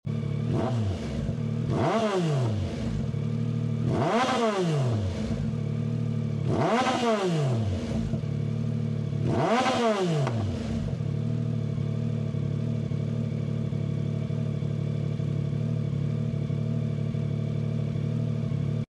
Dominator Mp3 Sound Effect 🔊 MT09 2024 | Dominator GP3 exhaust sound using DJI Mic 2.